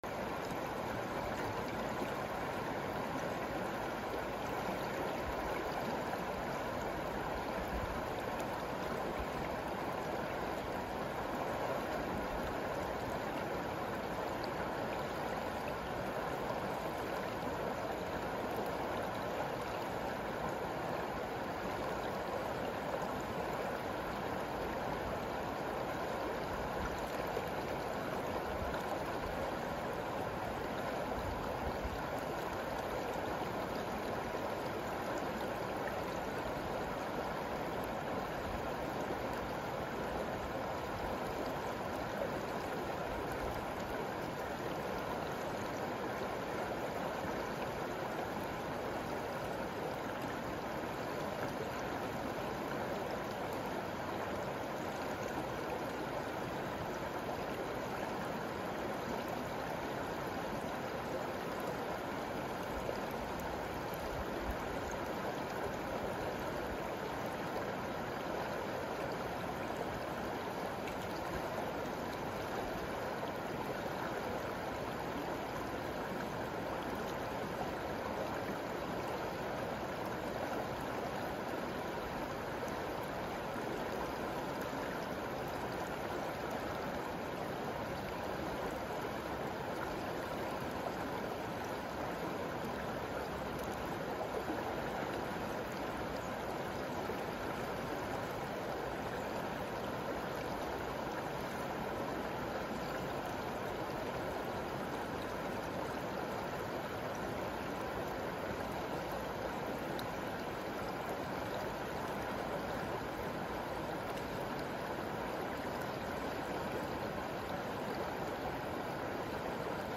Here is a 2min river meditation, created in Okutama, Japan.
Listen to the river, each time you notice your mind has wandered, gently go back to listening to the river.
river-meditation.mp3